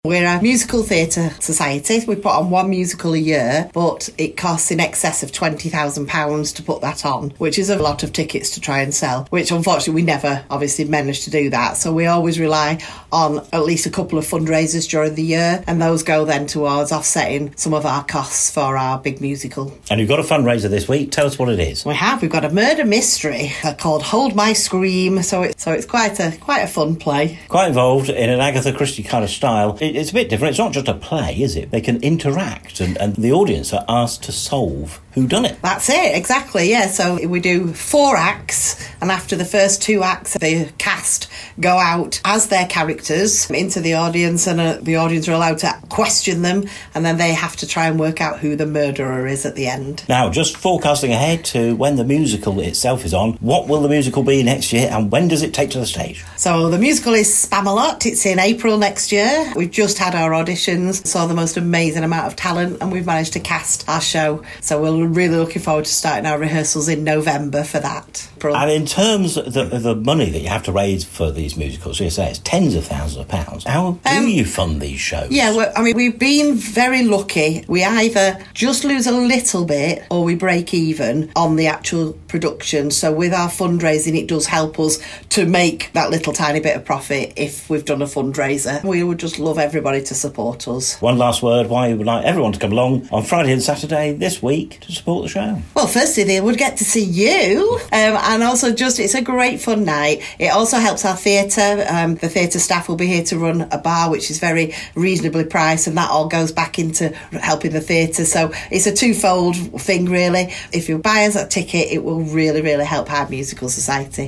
talks to Tameside Radio about the society, the next big show and why the fundraising events the society holds - like its murder mystery show - are so important.